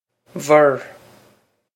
bhur vur
This is an approximate phonetic pronunciation of the phrase.